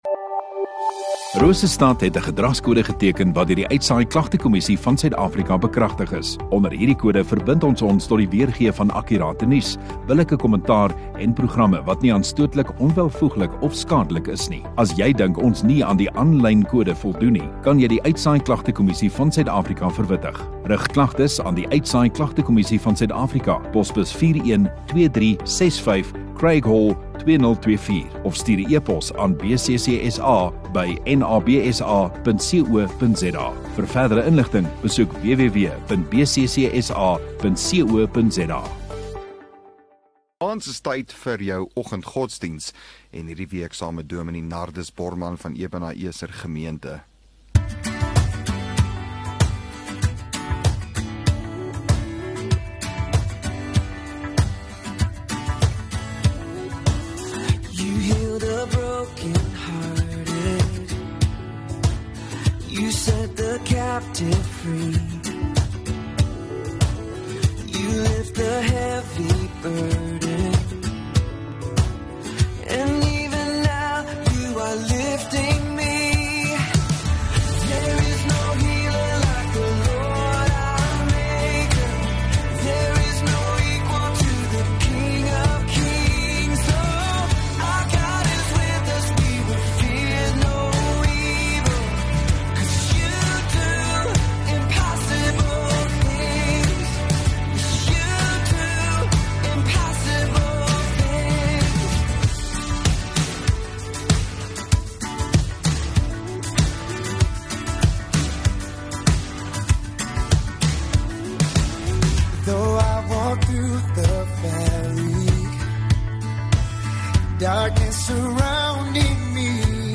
30 May Vrydag Oggenddiens